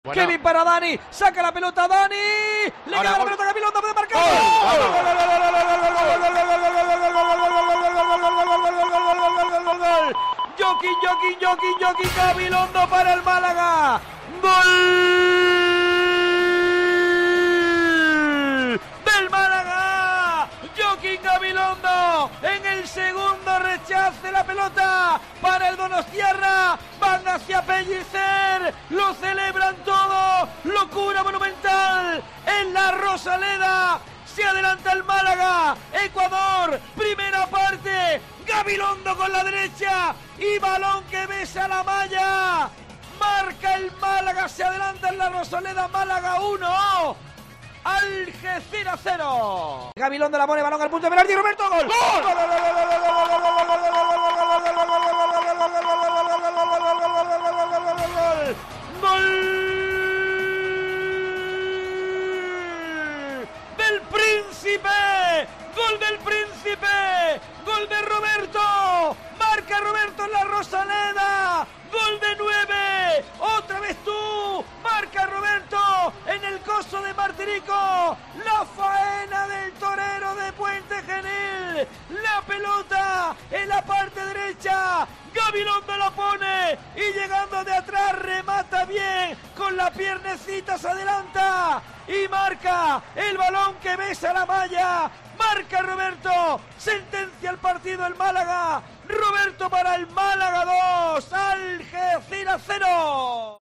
Así te hemos narrado los goles del Málaga en la victoria ante el Algeciras (2-1)